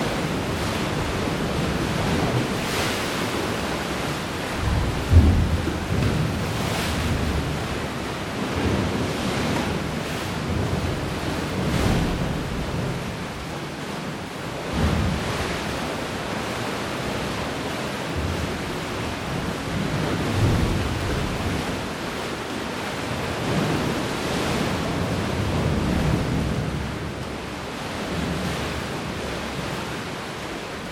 2 -- La mer dans les rochers